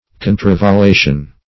Contravallation \Con`tra*val*la"tion\, n. [Pref. contra- +